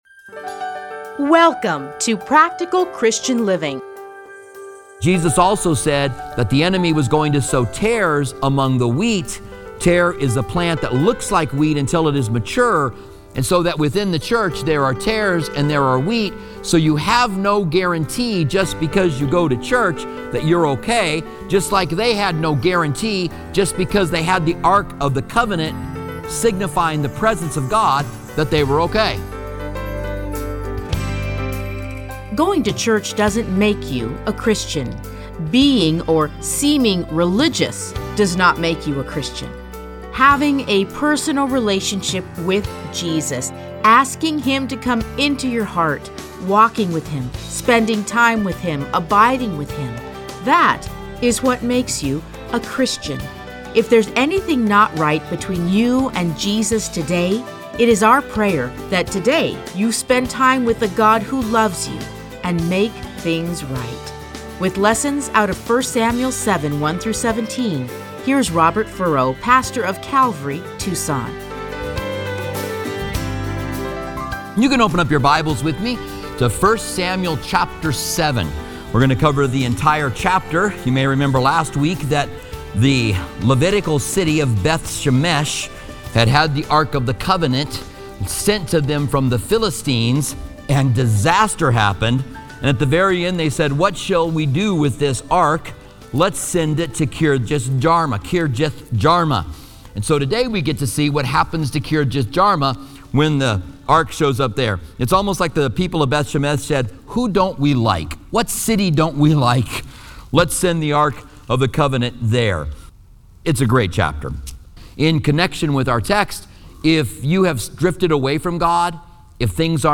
teachings are edited into 30-minute radio programs titled Practical Christian Living. Listen to a teaching from 1 Samuel 7:1-17.